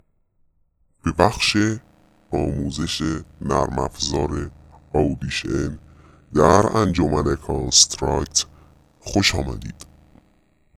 2- صدای کاراکتر (ترسناک)